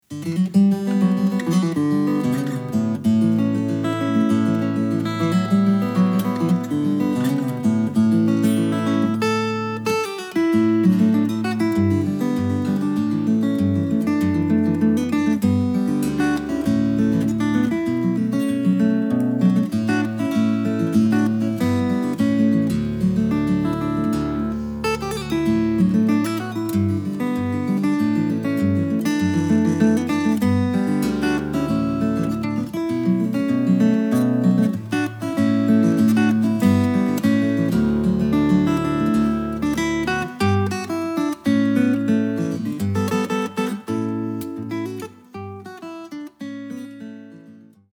this album of solo guitar pieces is great for all occasions.
A variety of guitars were used during the performances.